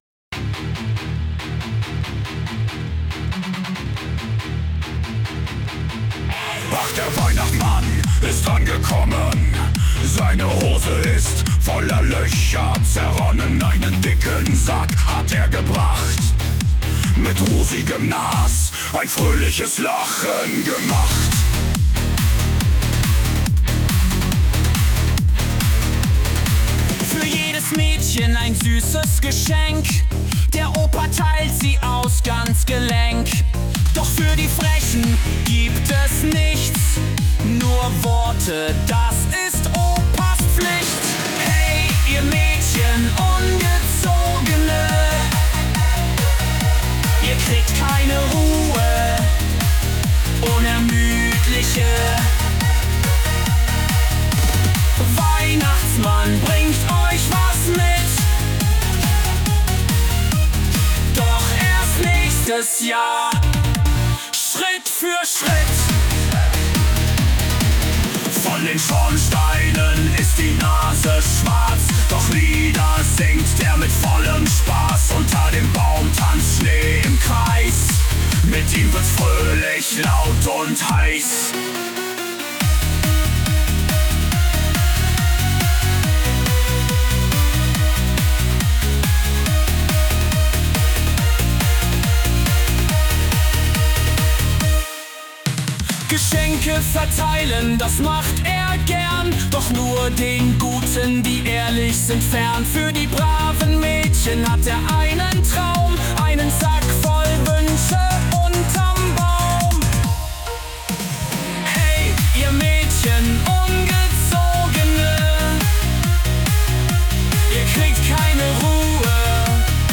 Germany, Pop, Rock, Metal, Punk | 20.03.2025 21:37